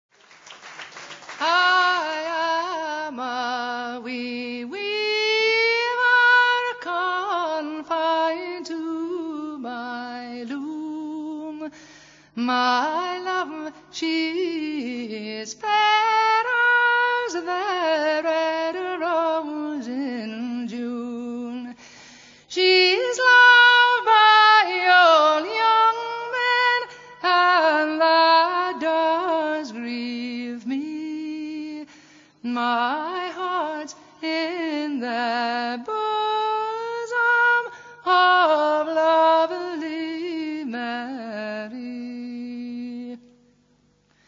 live recorded
First part, 0:44 sec, mono, 22 Khz, file size: 170 Kb.